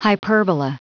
Prononciation du mot hyperbola en anglais (fichier audio)
Prononciation du mot : hyperbola